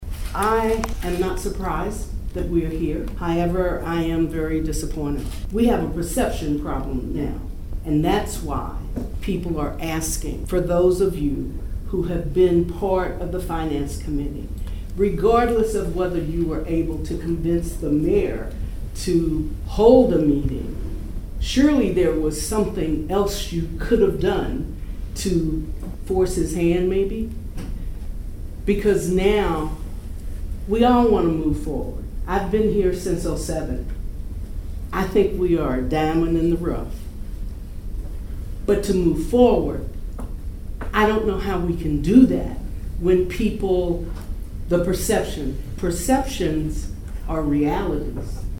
Approximately 75 community members attended  a special called Martin City Board Meeting  last night at the  Martin Municipal Building where acting Mayor David Belote held an open forum to allow residents who share their concerns about City Hall.